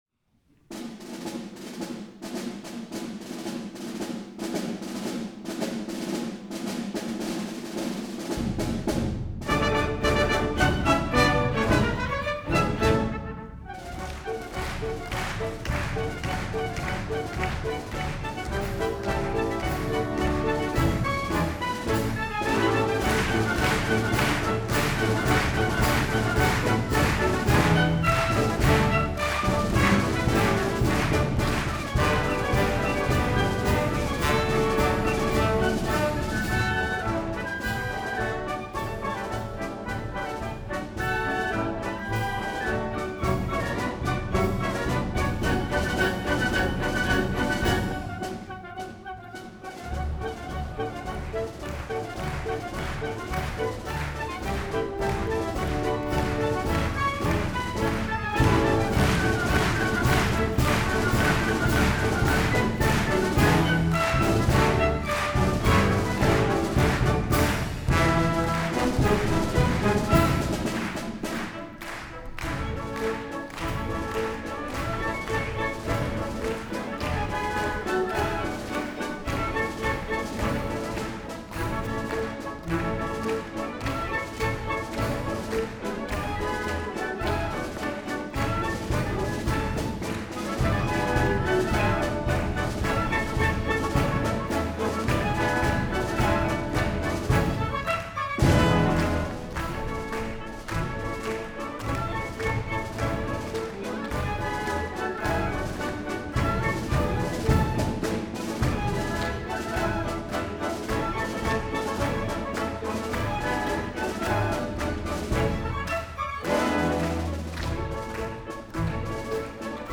Concert du nouvel an 2026
(Wav stéréo 48Khz 24Bits non compressé, nécessite un haut-débit)